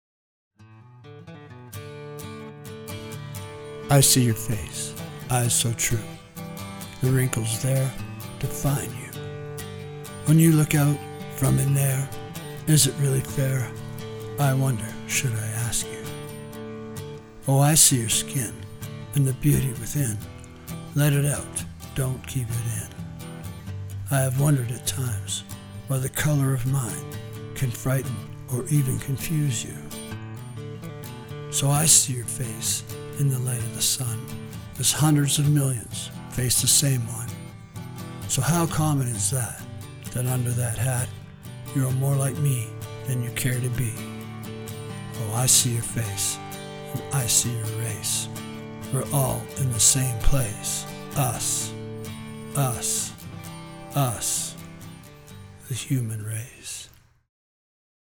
Spoken Word: